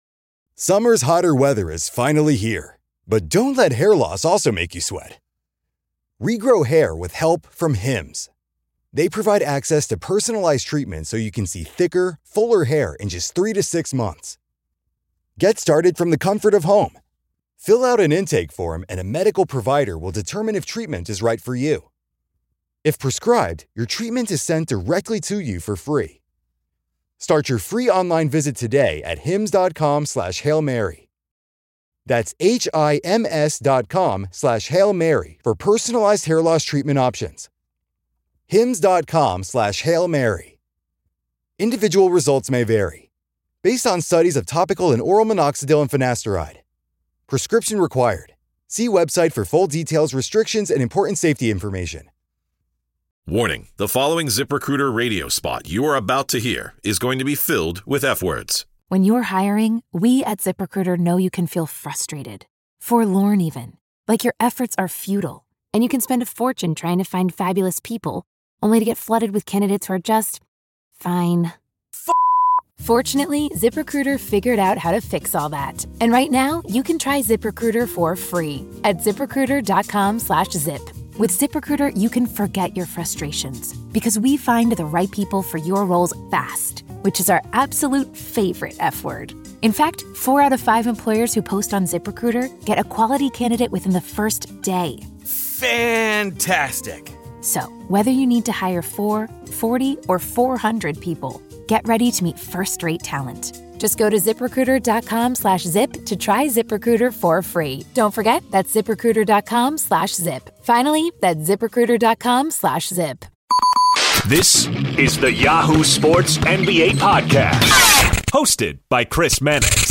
Walter McCarty The Crossover NBA Show SI NBA Basketball, Sports 4.6 • 641 Ratings 🗓 28 March 2018 ⏱ 36 minutes 🔗 Recording | iTunes | RSS 🧾 Download transcript Summary Bonus pod! Joining Chris Mannix of Yahoo Sports is Walter McCarty, the former Celtics assistant and new Evansville head coach.